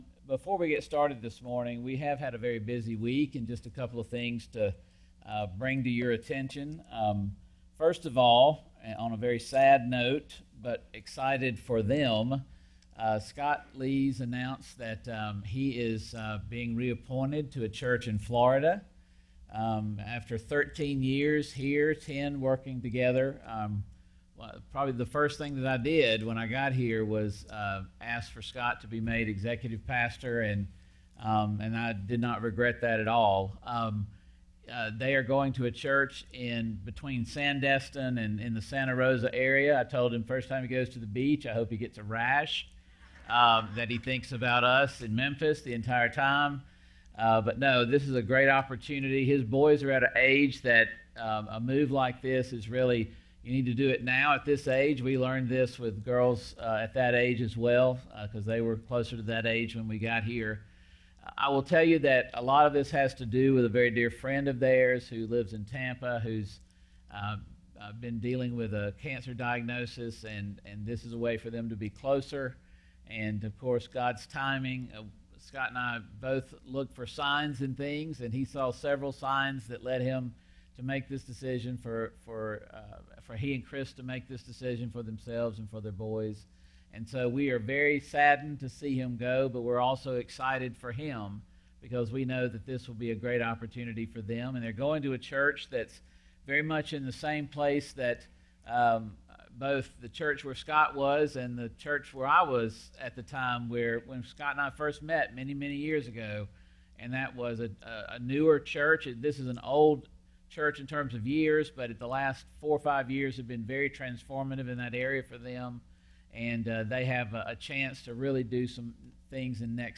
A message from the series "Colossians." The final week of our Colossians series.